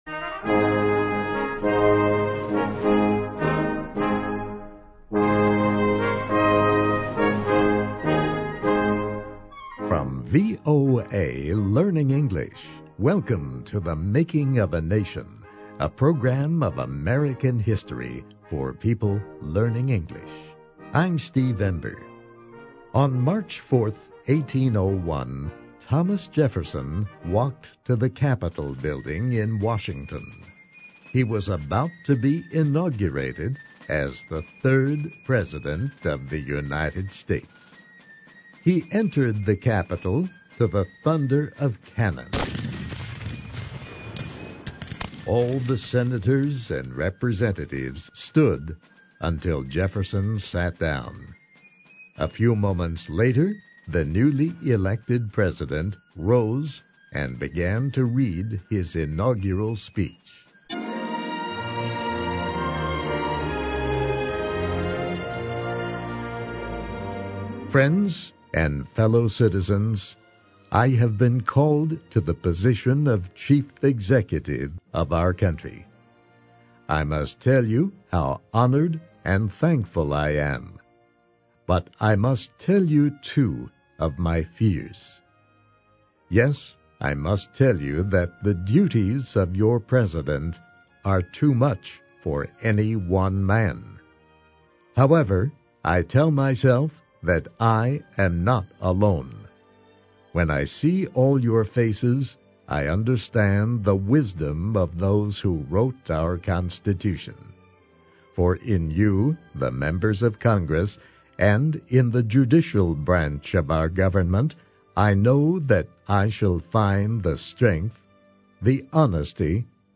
Welcome to THE MAKING OF A NATION – an American history series in VOA Special English.